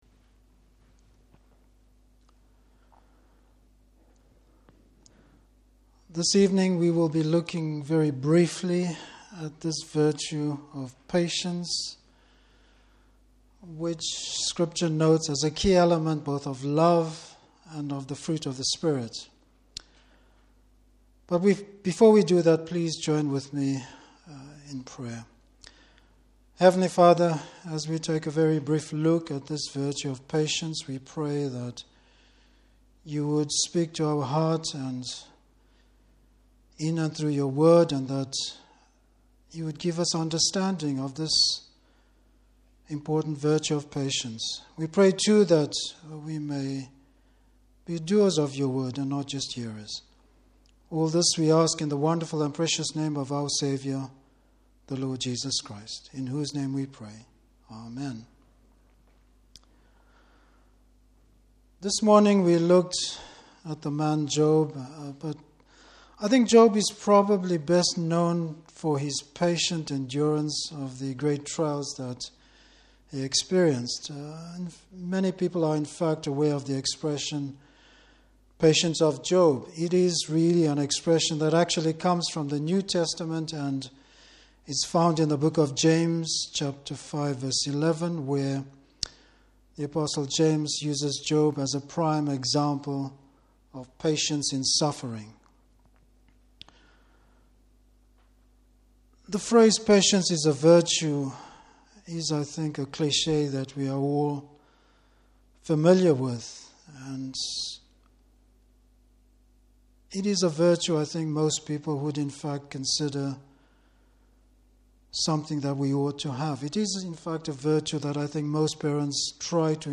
Service Type: Evening Service Bible Text: Galatians 5:22-23.